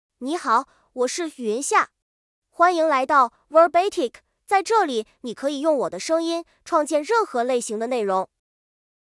YunxiaMale Chinese AI voice
Yunxia is a male AI voice for Chinese (Mandarin, Simplified).
Voice sample
Listen to Yunxia's male Chinese voice.
Male